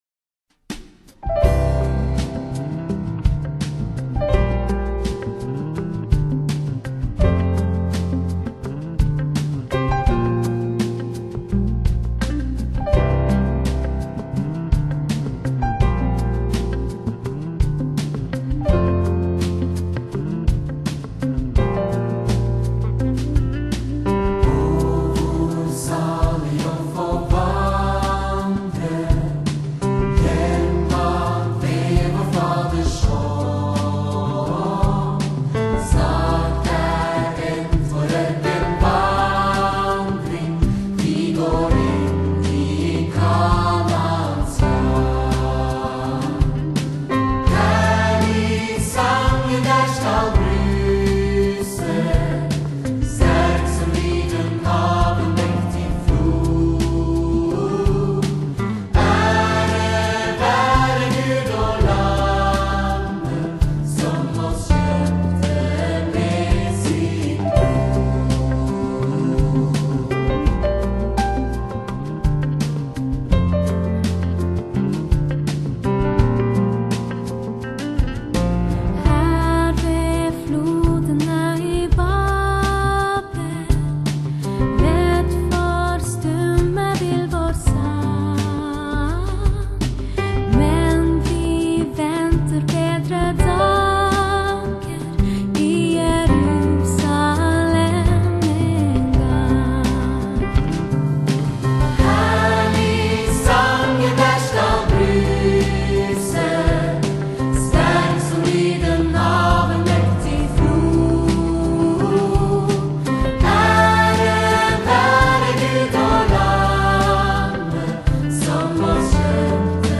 挪威首屈一指的福音團體